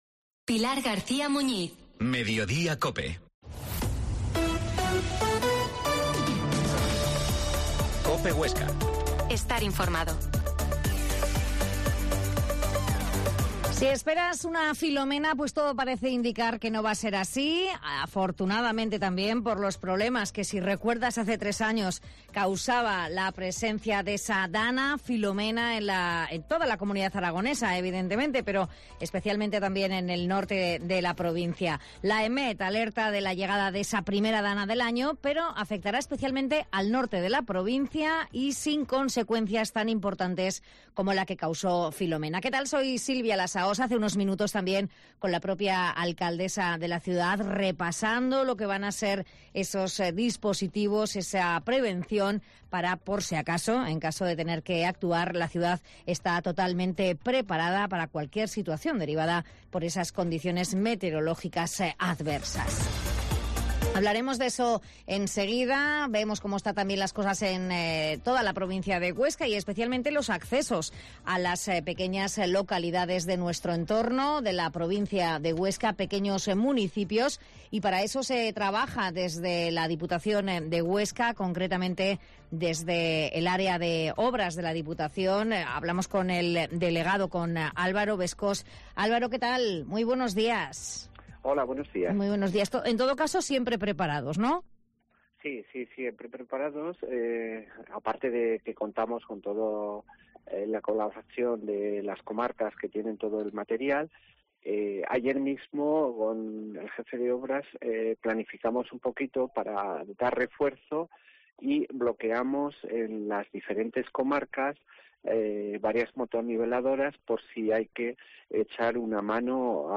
Mediodia en COPE Huesca 13.50 Entrevista al delegado de obras de la DPH, Alvaro Bescós